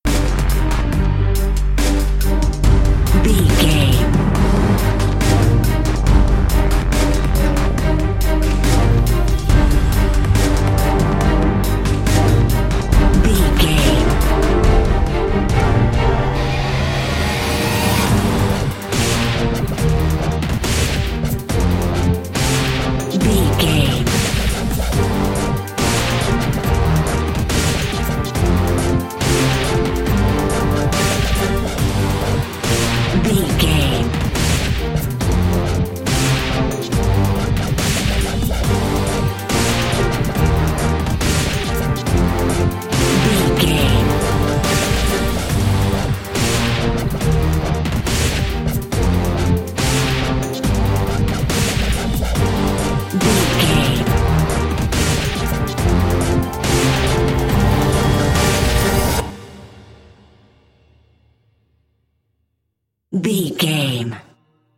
Epic / Action
Aeolian/Minor
strings
drum machine
synthesiser
brass
driving drum beat